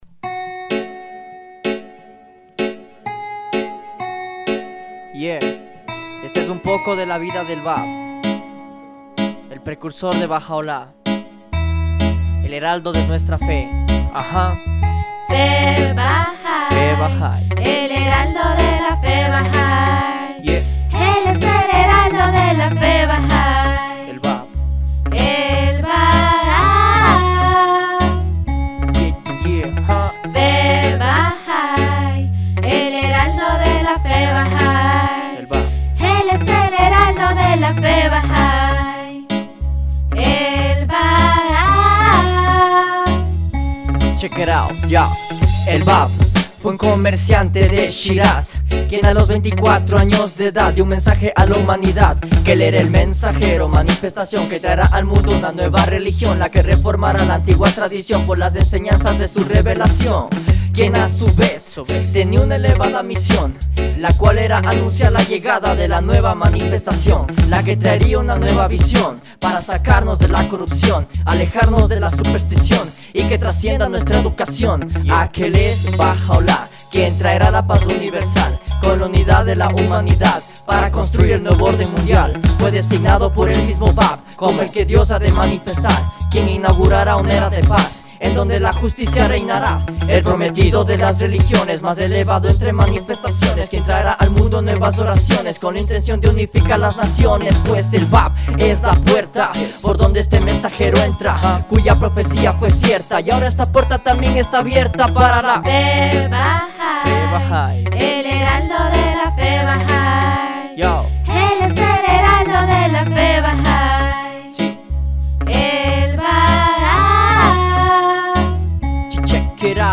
Musicians in Ecuador record song in honor of the bicentenary